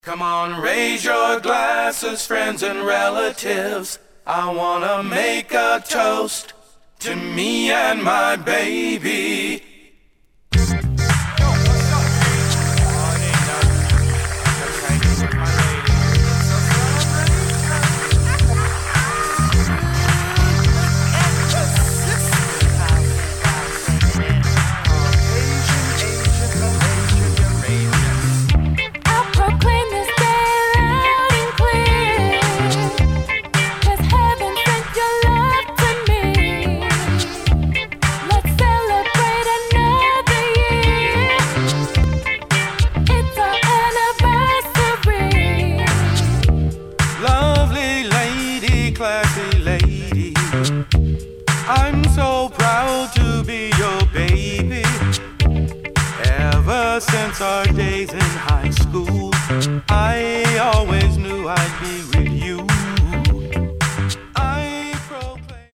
[ SOUL / HIP HOP / FUNK ]